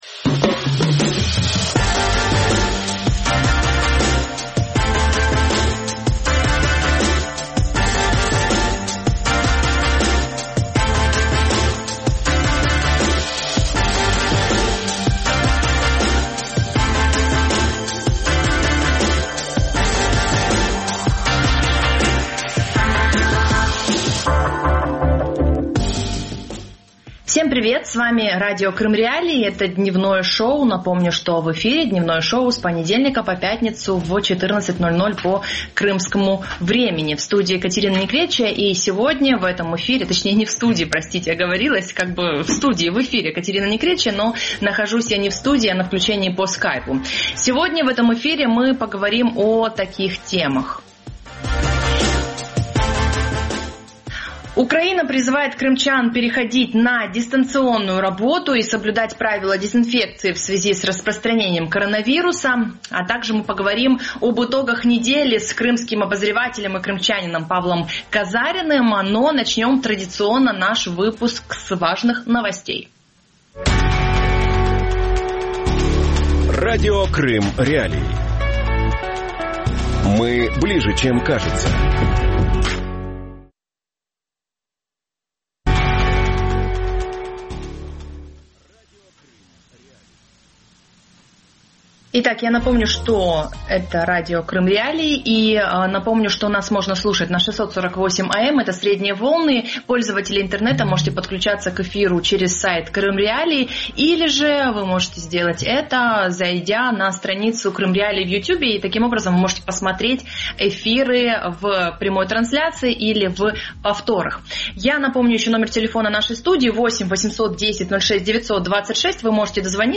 Крым. Путин. Коронавирус | Дневное ток-шоу